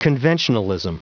Prononciation du mot conventionalism en anglais (fichier audio)
Prononciation du mot : conventionalism